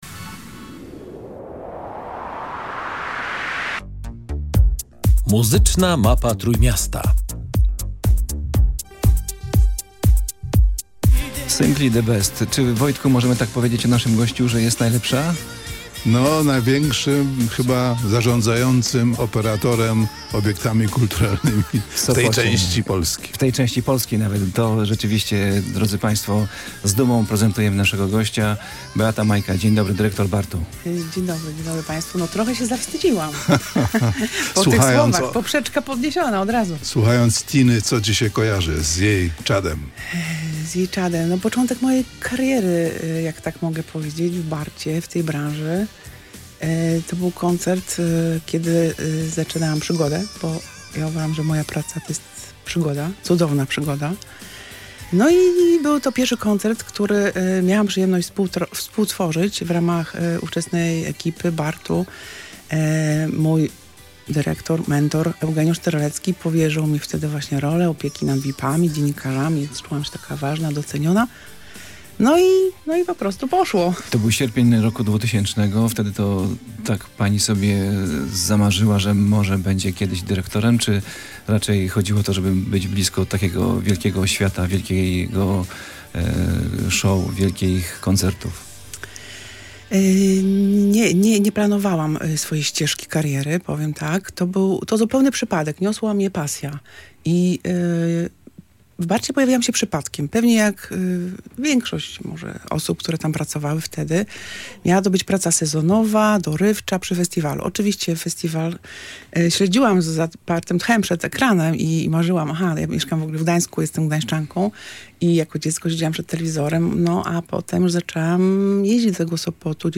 Rozmowa nie ogranicza się jednak tylko do kaprysów gwiazd. Opera Leśna to miejsce, gdzie kultura nierozerwalnie splata się z naturą – to właśnie przyroda nieraz wpływała na przebieg wydarzeń scenicznych.